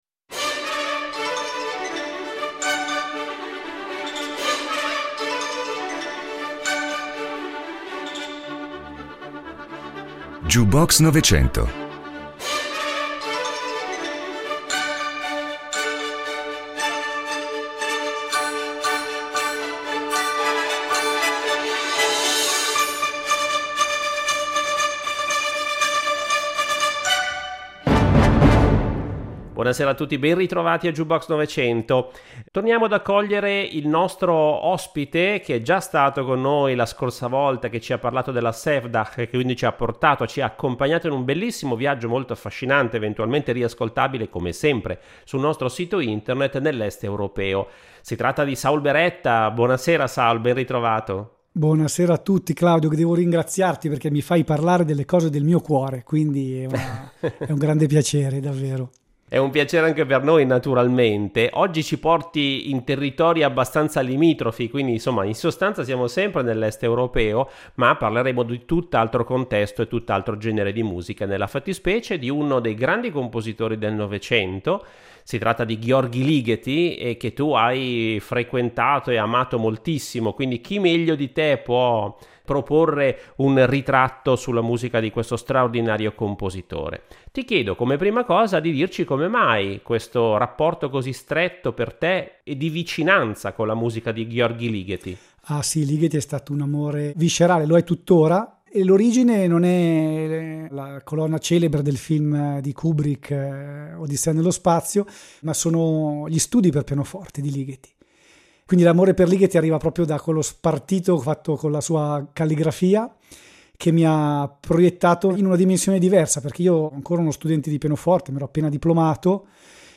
Ligeti piano works